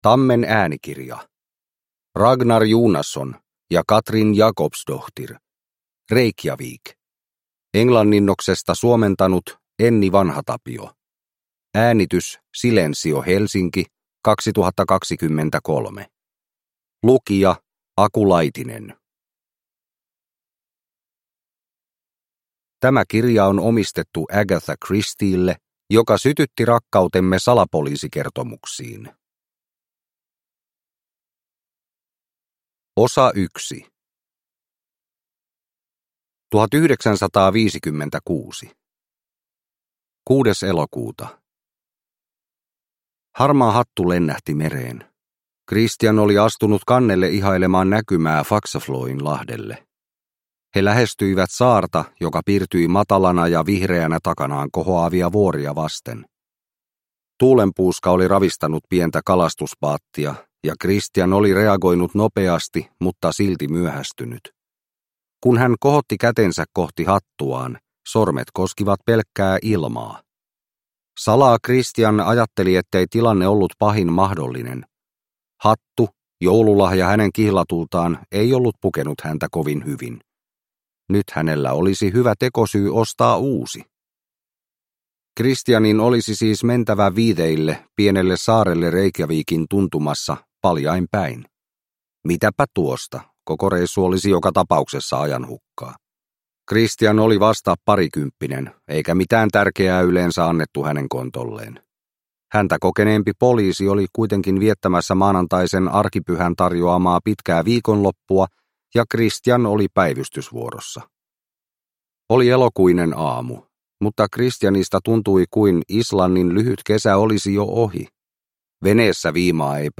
Reykjavik – Ljudbok – Laddas ner